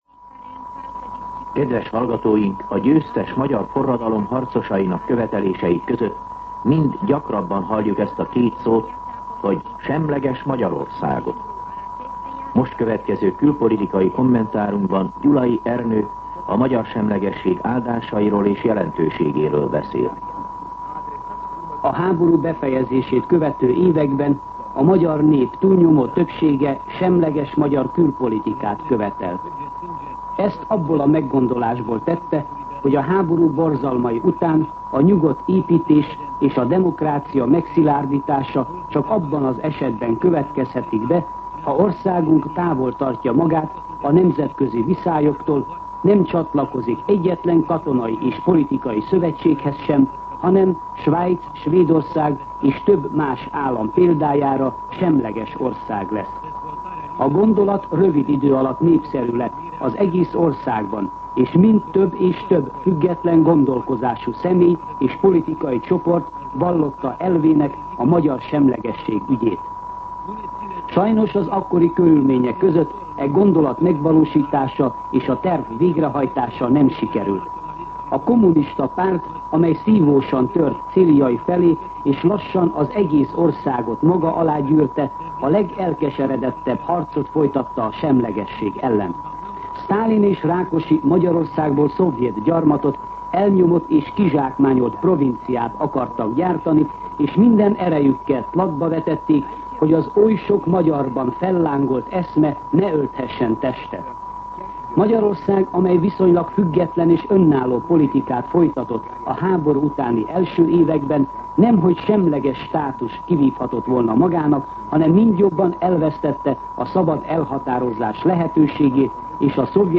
Külpolitikai kommentár